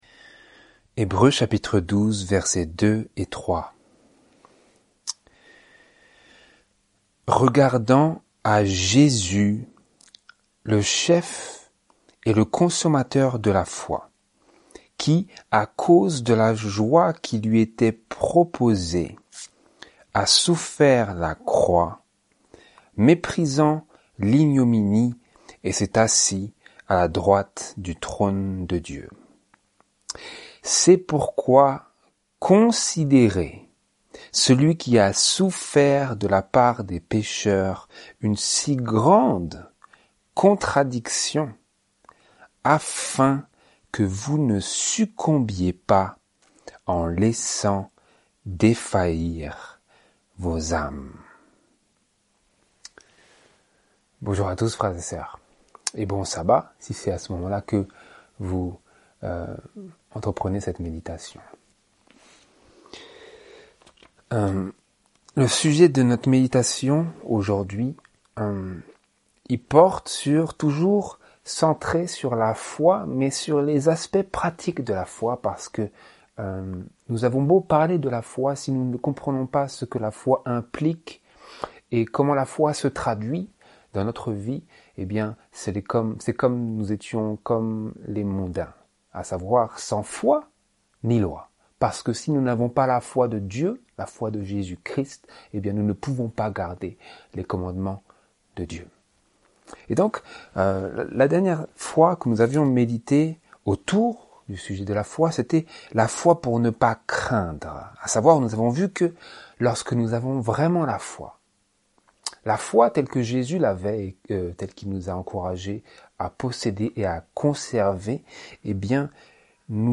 Notre dernier sermon